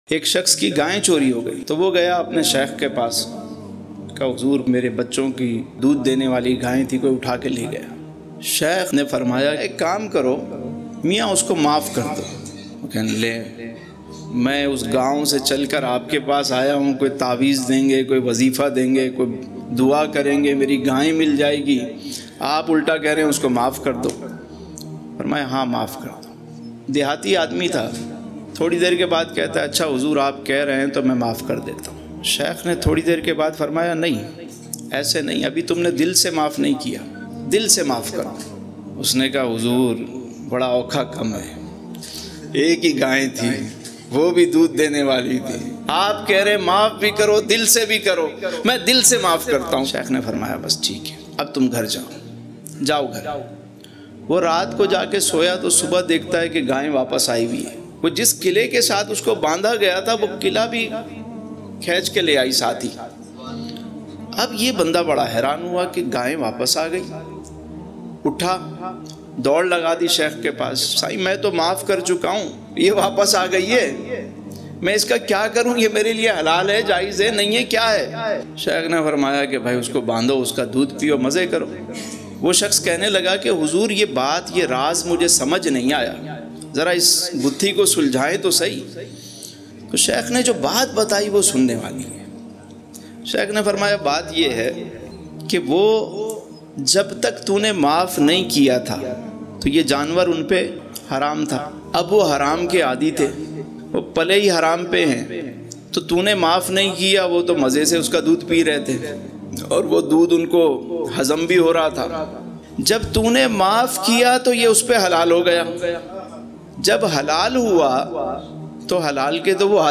Muafi Ka inaam Emotional Mp3